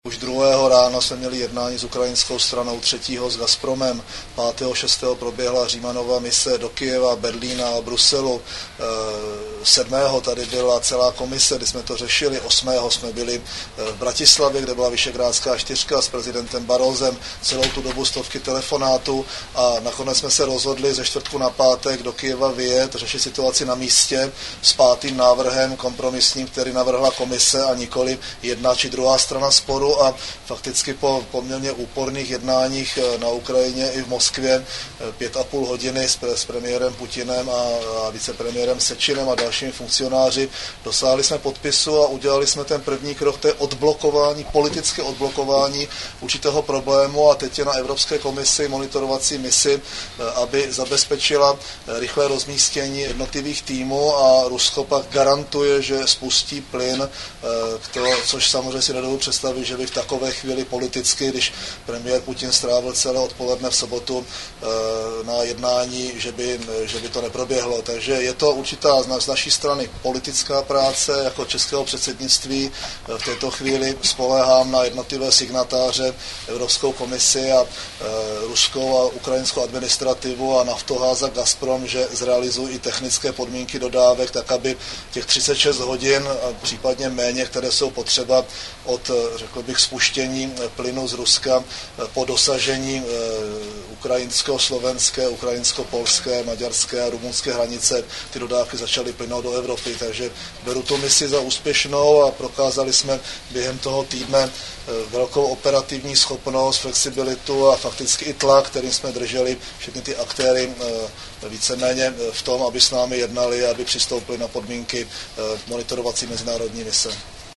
Komentář premiéra Mirka Topolánka k průběhu vyjednávání s Ruskou federací a Ukrajinou